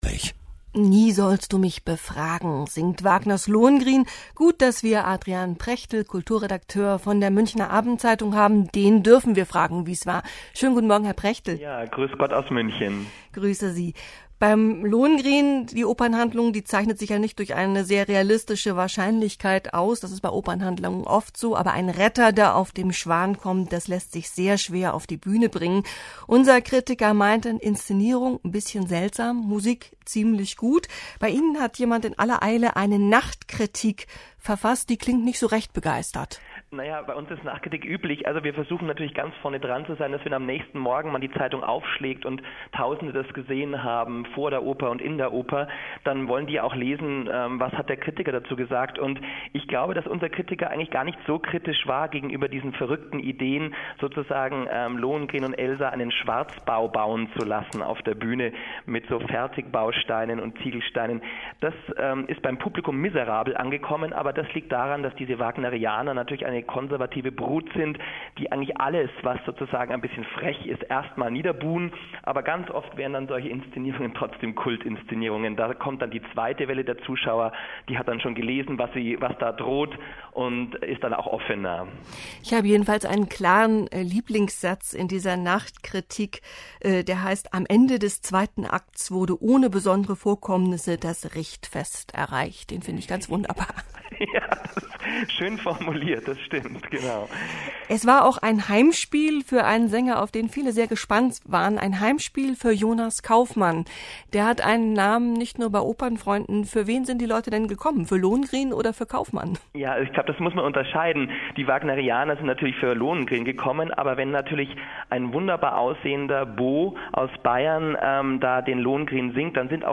Feuilletonpressegespräch